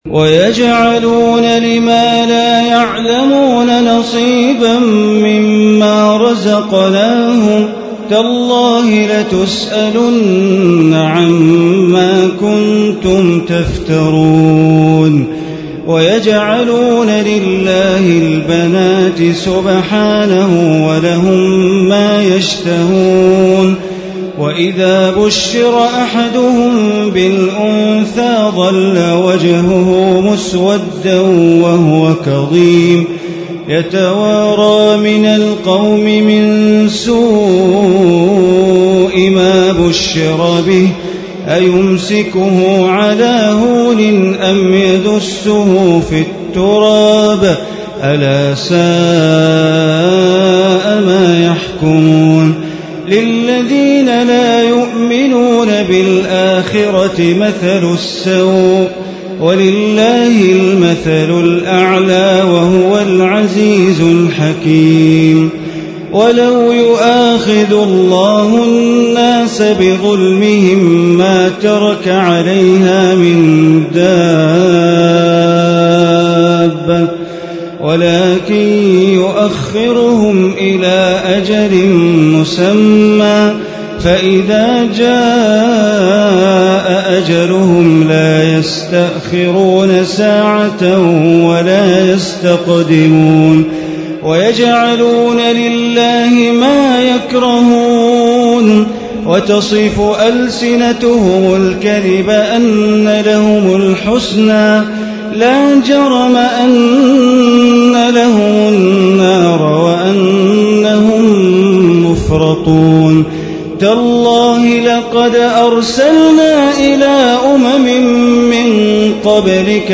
ليلة 13 من رمضان عام 1436 من سورة النحل آية 56 إلى آخر السورة > تراويح ١٤٣٦ هـ > التراويح - تلاوات بندر بليلة